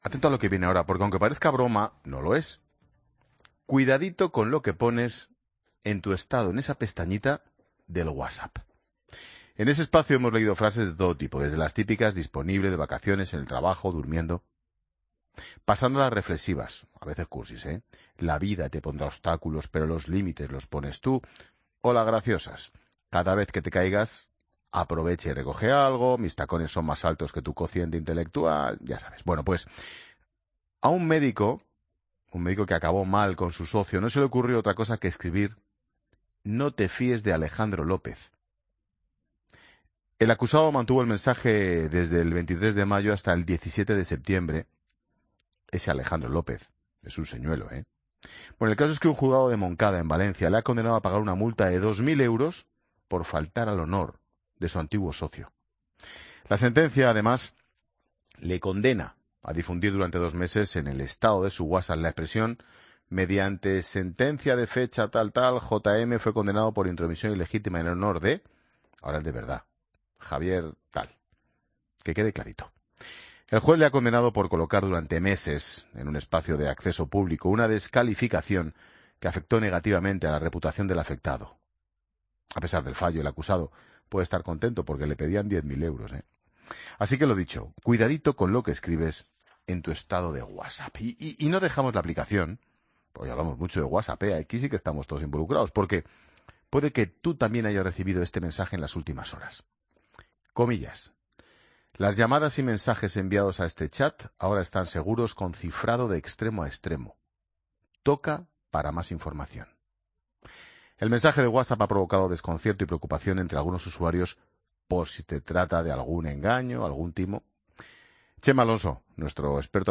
Escucha la entrevista a Chema Alonso, experto en seguridad informática